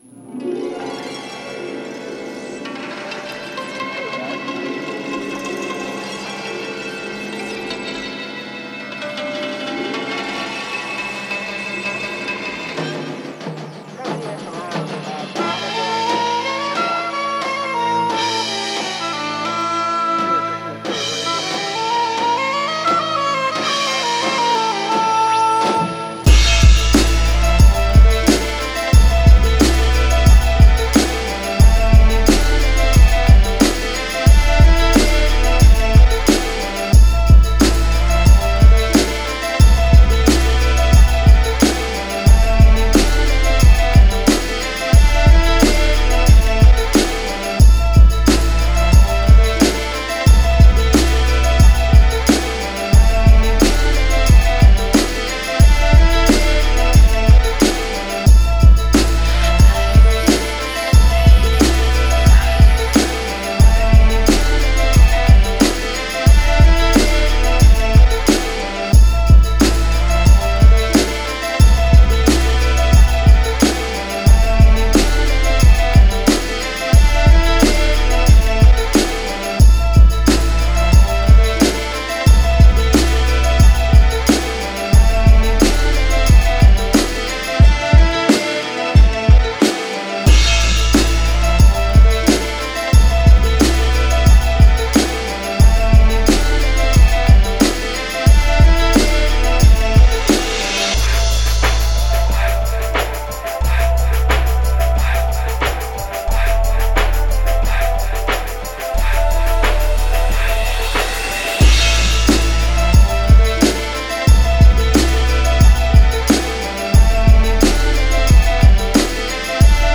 (Instrumental Project)
Genre: Hip-Hop.